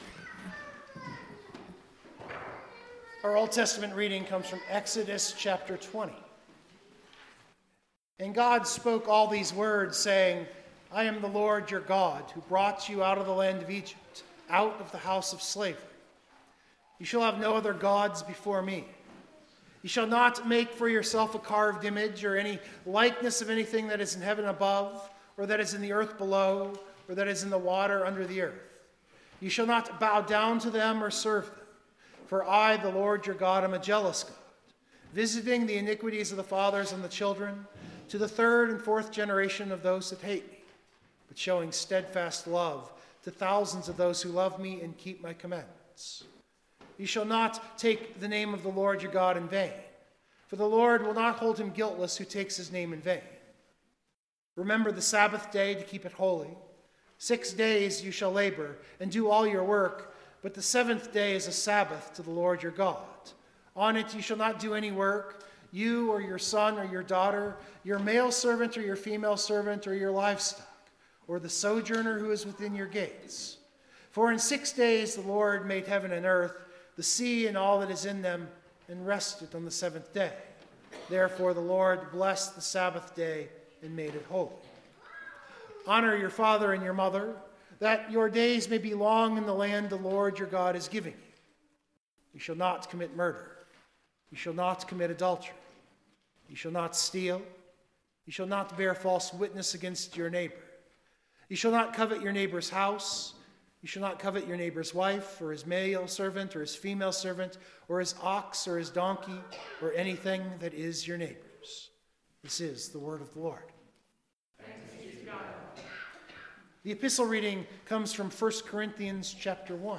This sermon might be a bit intellectual, but it is lent which is a season for some challenging fare. The challenge here is to think about what does the cleansing of the temple of our body.